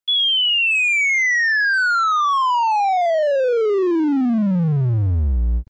جلوه های صوتی
دانلود صدای بمب 3 از ساعد نیوز با لینک مستقیم و کیفیت بالا